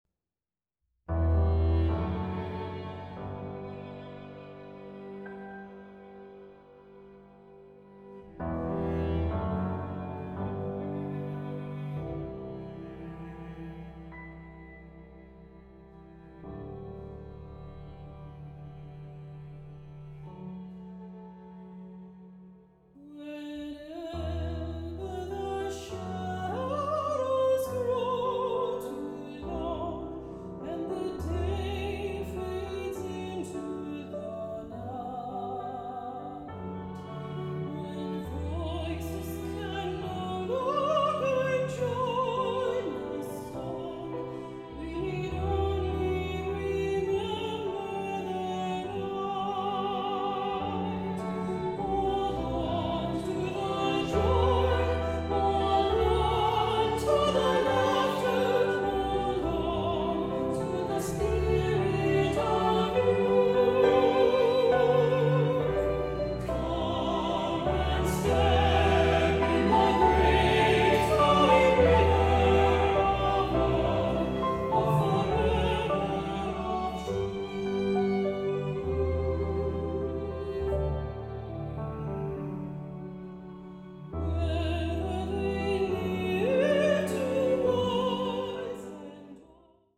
Choral Concert/General Male Chorus
TTBB